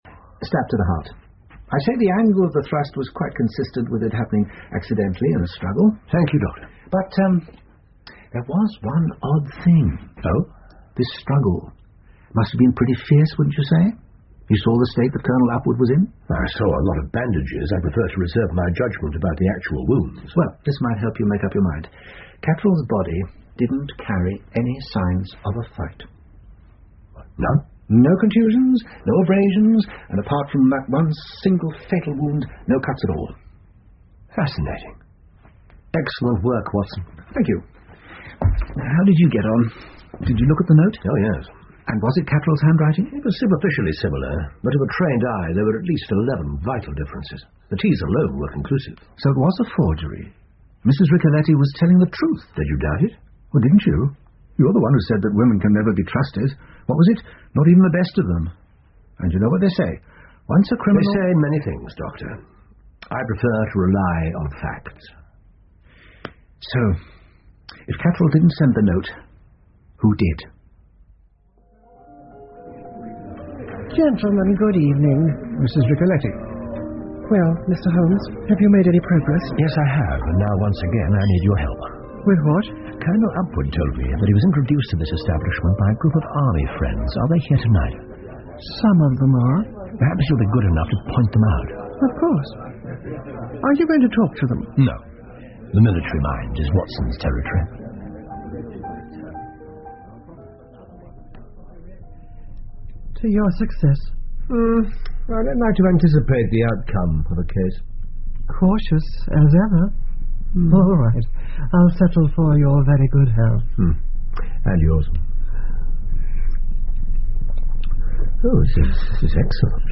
福尔摩斯广播剧 The Striking Success Of Miss Franny Blossom 6 听力文件下载—在线英语听力室